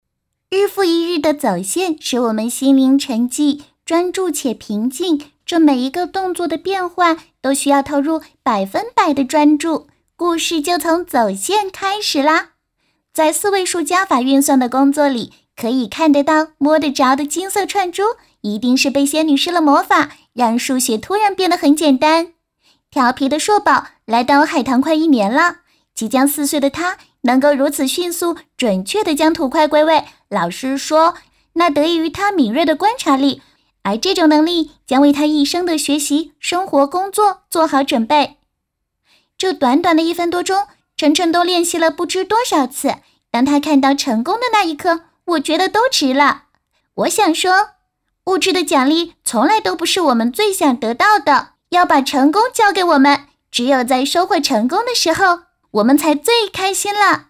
仿女童-女2-成品.mp3